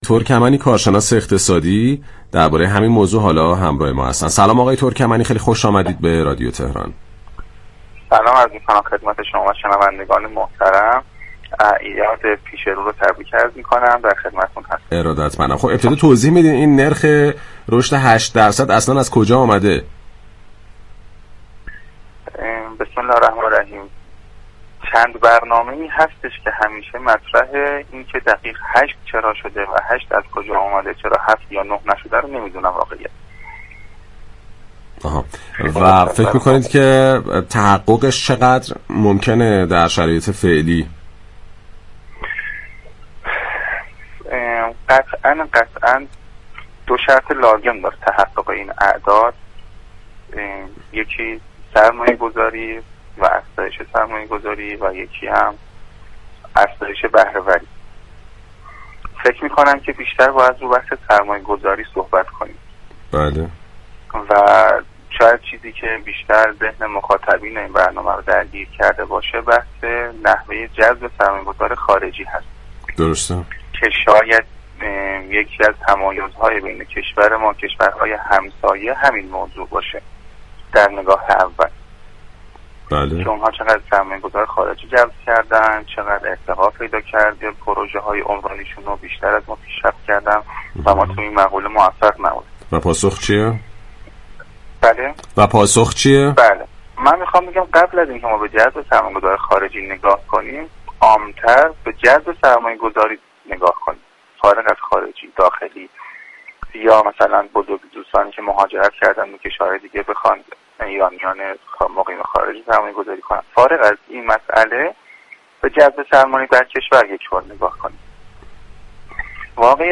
رویداد
گفت و گو